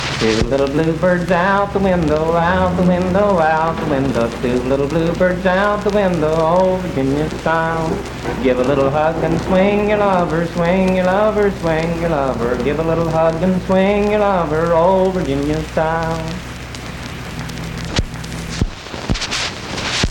Unaccompanied vocal performance
Dance, Game, and Party Songs
Voice (sung)
Spencer (W. Va.), Roane County (W. Va.)